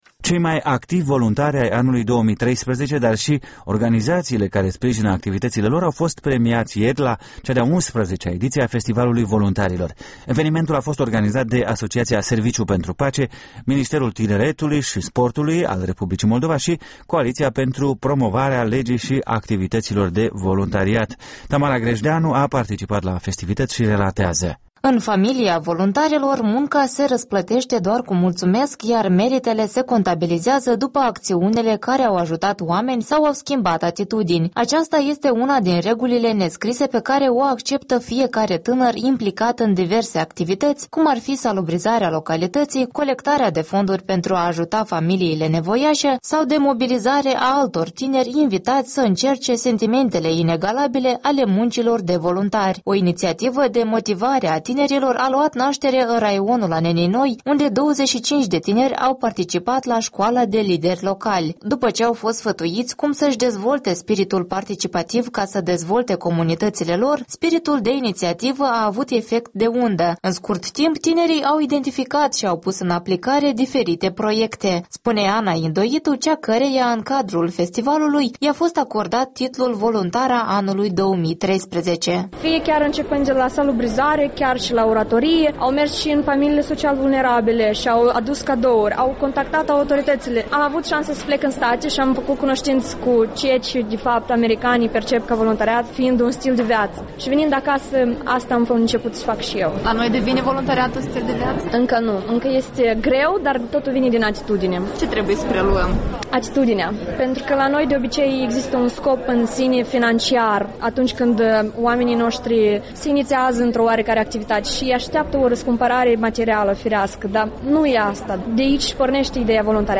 Un reportaj de la al IX-lea Festival al voluntarilor